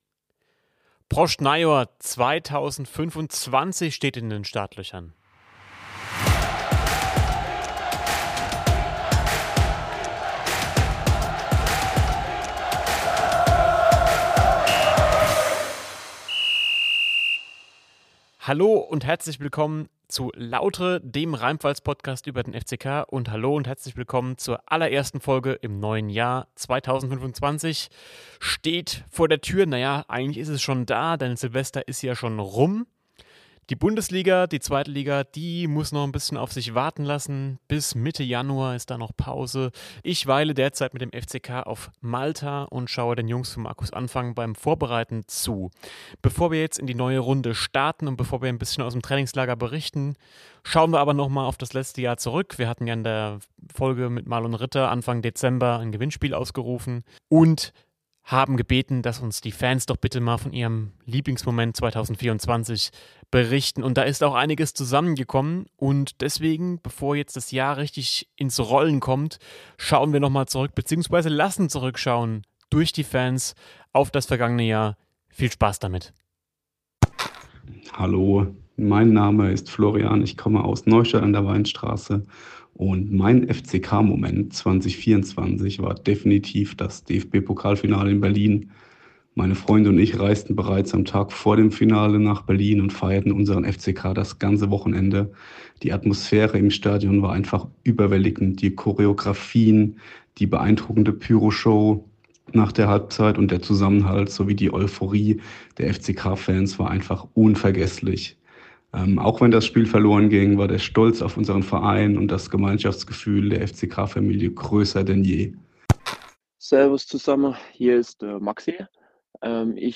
In der ersten Folge 2024 berichten Fans vom Pokalfinale, vom Halbfinale, aber auch von denkwürdigen Auswärtsfahrten.